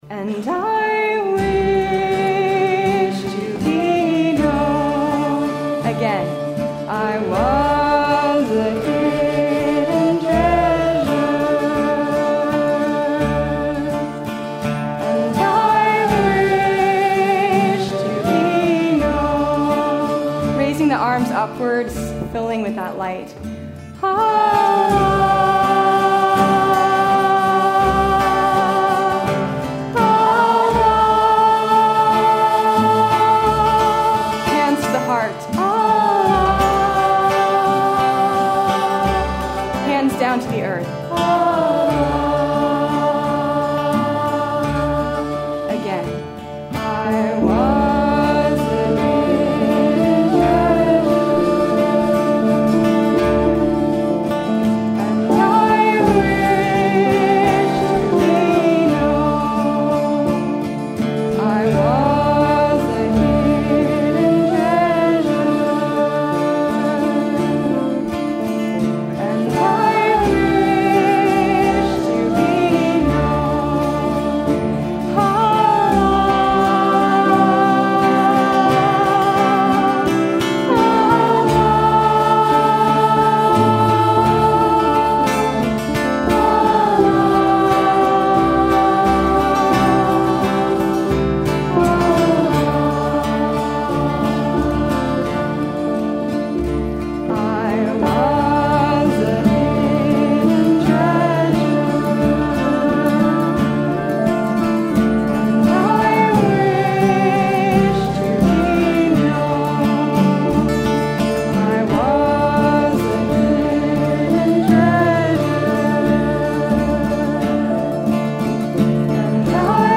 NW Sufi Camp 2012